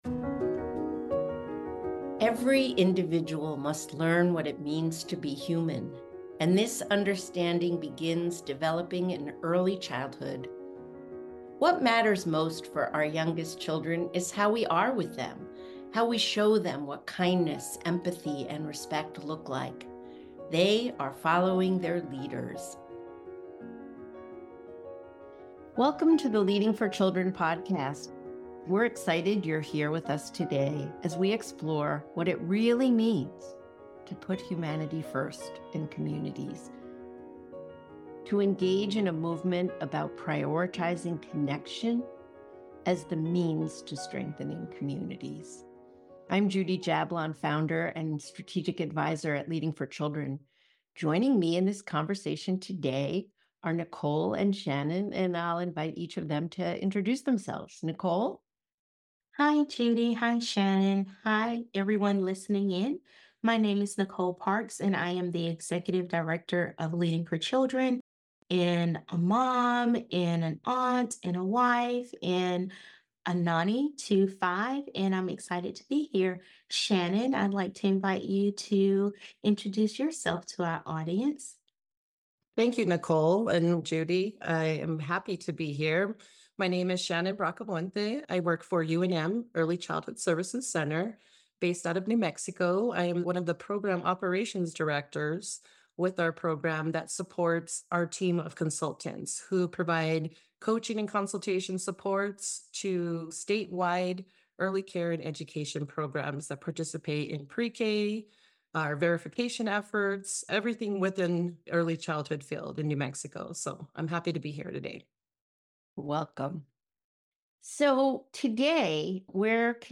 In Season 2 Episode 2 of The Leading for Children Podcast, guests explore nurturing equitable communities by centering humanity, relationships, and shared responsibility.